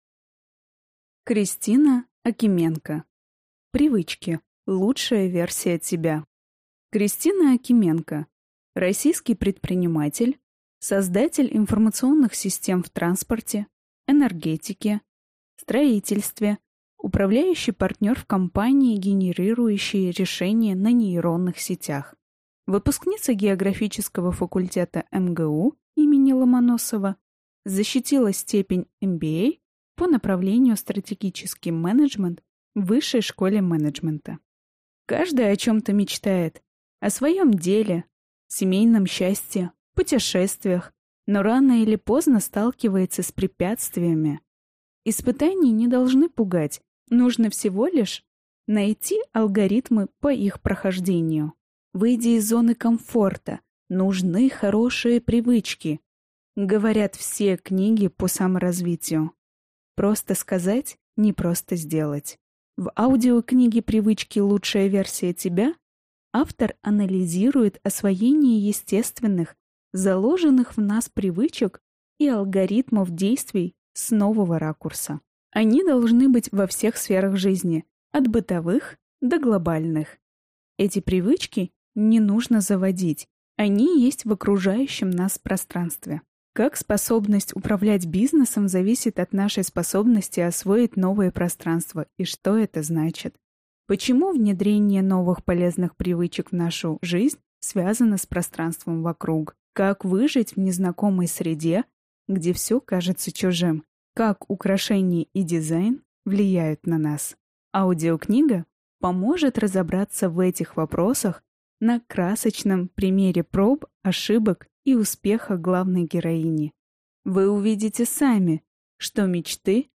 Аудиокнига Привычки: лучшая версия тебя | Библиотека аудиокниг